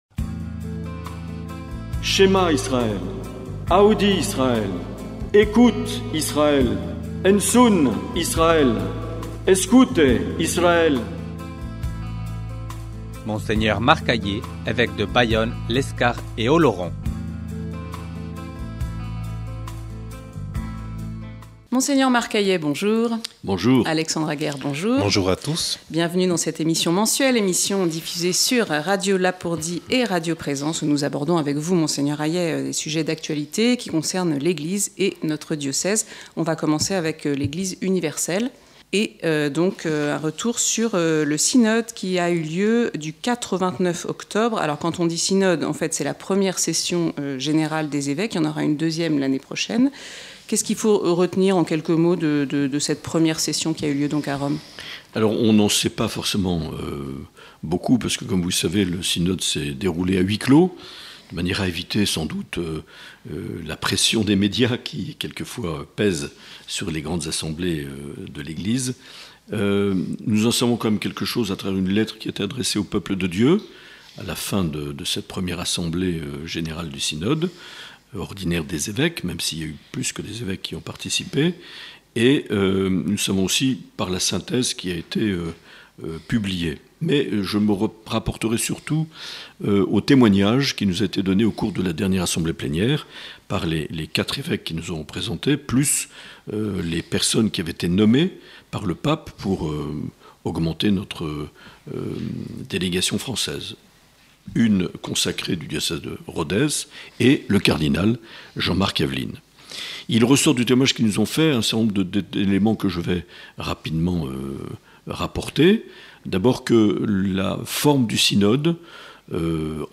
L’entretien avec Mgr Aillet - Décembre 2023